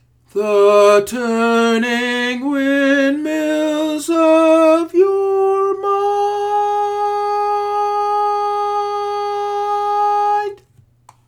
Key written in: A♭ Major
Type: Barbershop
Each recording below is single part only.